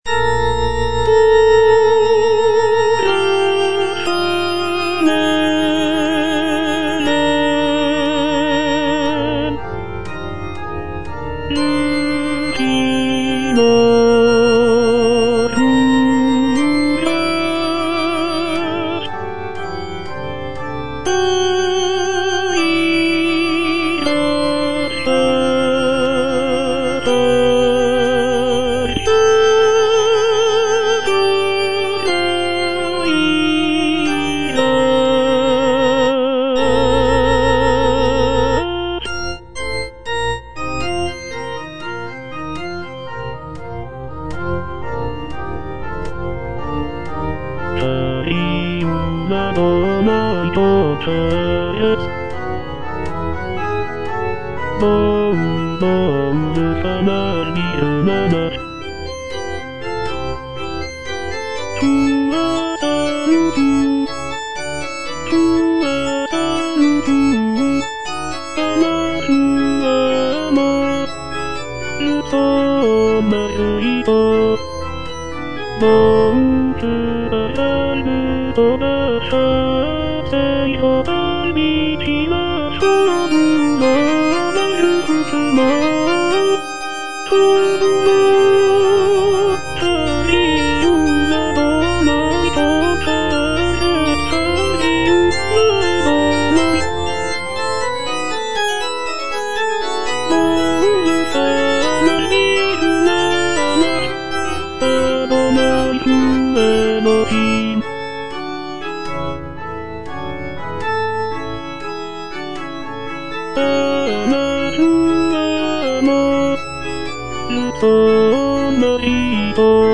(tenor II) (Voice with metronome) Ads stop
choral work